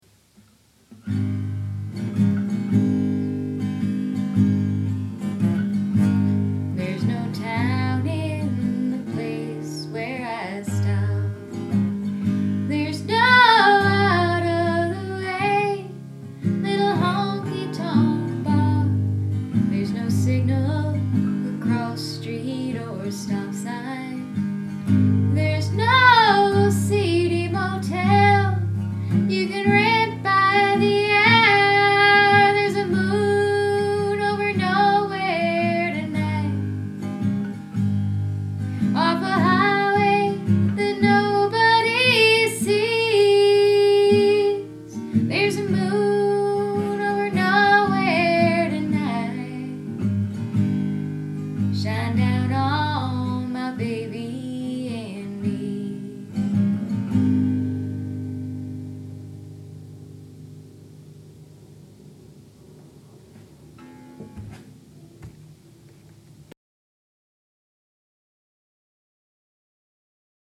Moon Over Nowhere (demo) - MP3
Verse G D G C G G D G C A D
Chorus C G C A D C G G D G